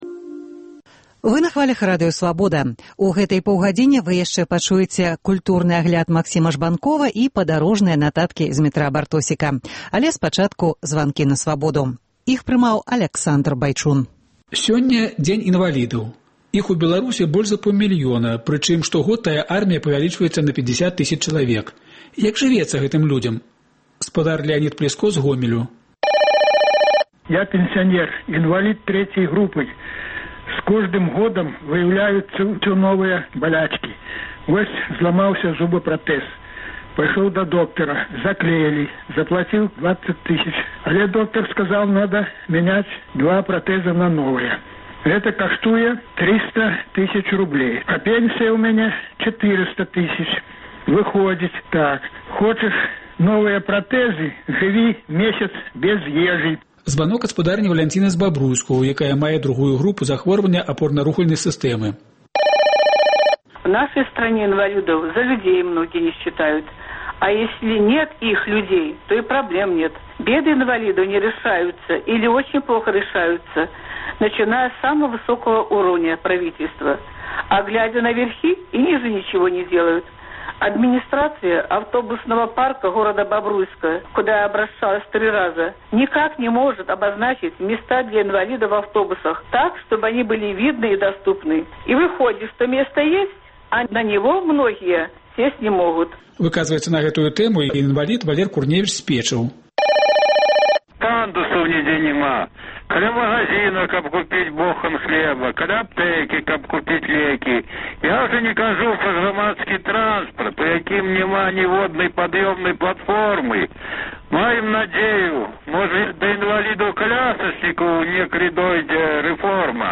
У сёньняшніх “Званках на Свабоду” слухачы выказваюцца з нагоды Міжнароднага дня інвалідаў, рэагуюць на павелічэньне кантынгенту амэрыканскіх войскаў у Аўганістане, разважаюць над праблемай аўтамабілізацыі краіны.